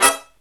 HIGH HIT06-R.wav